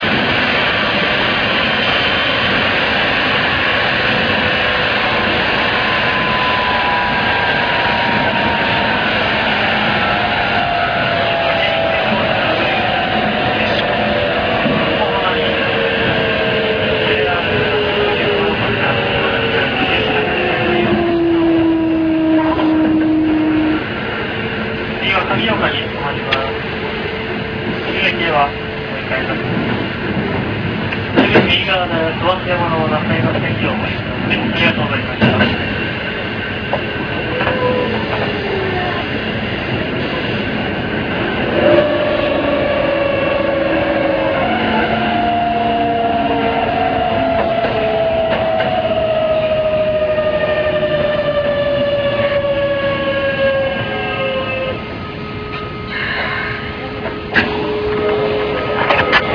600形(1C4M三菱減速)[kq650mb.ra/116KB]
4次車では東洋VVVFを積んだ編成にはあまり音が変わったようなことはなかったのですが、 三菱VVVFを積んだ編成はかなり変わっています。
また、1C8Mの東洋だけゴマカシがあるのですが、MDでは跡が残ってしまいますね・・・音質はさすがです。